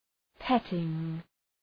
Προφορά
{‘petıŋ}